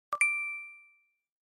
Description: Tiếng xác nhận thanh toán, âm báo thành công, âm thanh giao dịch Apple, tiếng beep thanh toán, âm báo ví điện tử, tiếng “ting” ngắn gọn, trong trẻo, báo hiệu giao dịch thanh toán thành công qua Apple Pay.
am-thanh-apple-pay-www_tiengdong_com.mp3